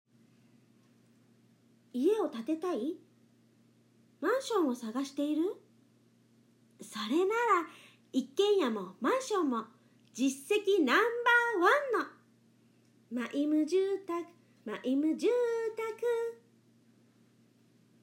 出⾝地・⽅⾔ 大阪府・関西弁
ボイスサンプル
CM
ナレーション（関西弁）
セリフ（関西弁）